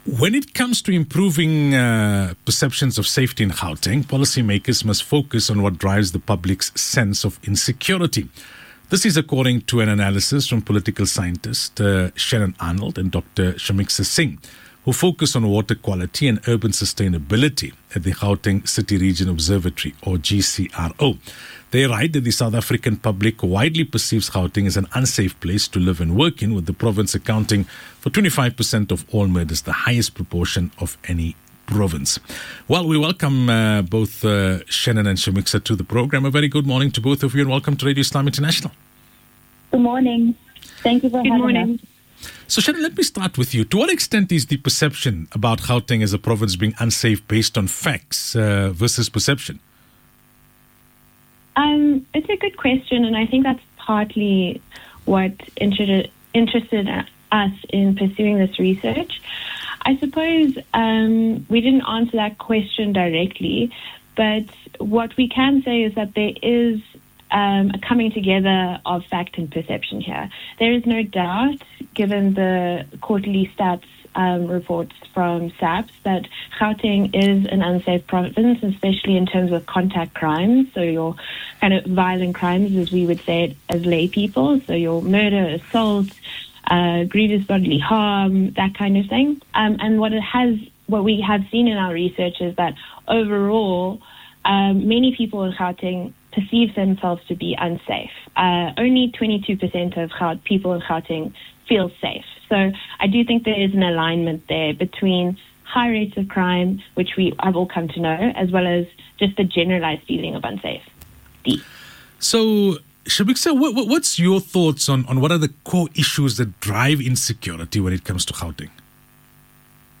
GCRO's researchers, interviewed in '